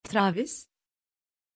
Aussprache von Travis
pronunciation-travis-tr.mp3